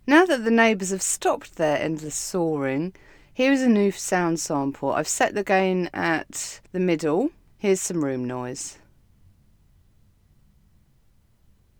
The DIY has stopped, although I can’t control the trains and aeroplanes…
I’m still using the Yeti as my audio interface has to be ordered and will take a month or two to come.
So, for now, with a bit more padding in the room to reduce that boxy sound, here is my sample of raw audio: /uploads/default/original/2X/8/86c7fe8183c95f1b626eed0cdffa1262b73580a3.wav The process we had before was: